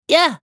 少年系ボイス～戦闘ボイス～
【攻撃（弱）3】